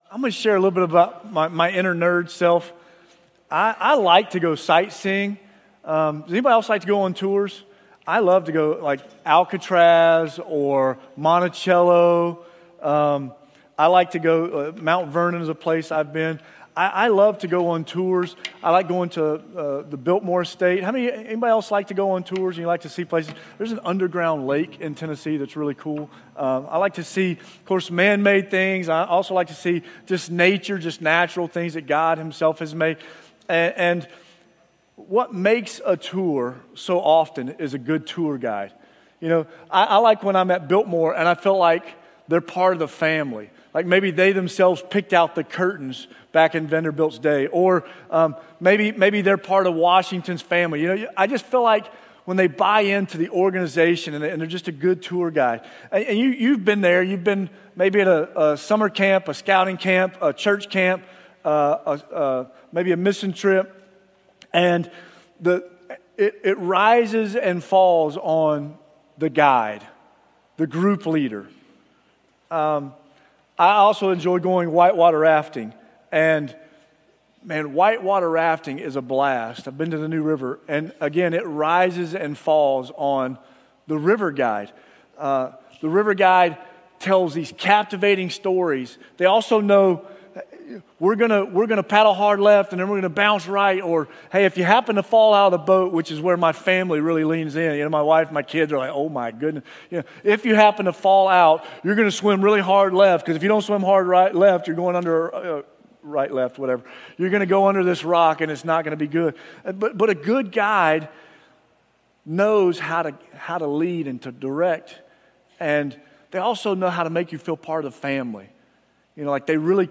Sermons | Jefferson Christian Church
Sermon Notes